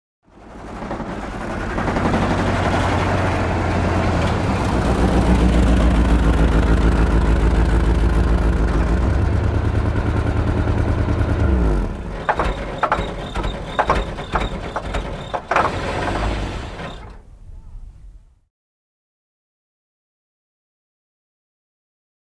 1964 Cadillac; In Very Slow, Then Rough Idle Take Out Of Gear, And Off With Long Diesel Clanks And Hiss.